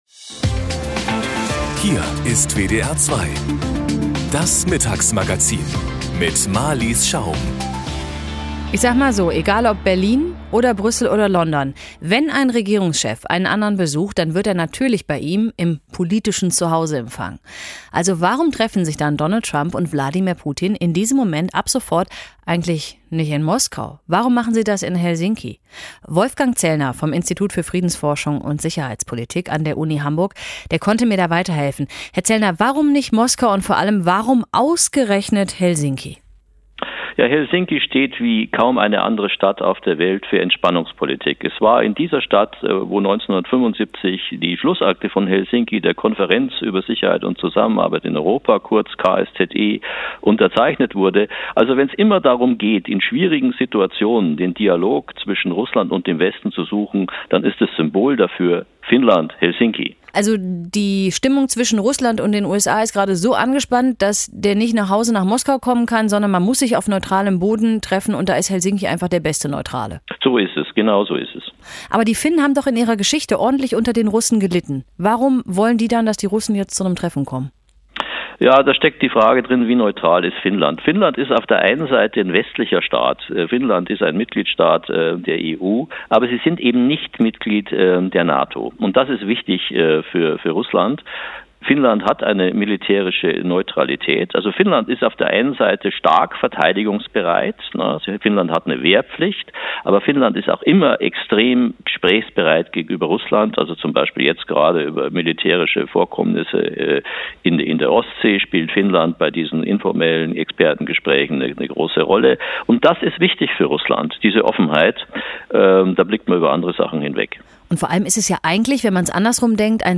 Treffen zwischen US-Präsident Trump und dem russischen Präsidenten Putin: Warum findet es ausgerechnet in Helsinki statt? Interview im Mittagsmagazin von WDR 2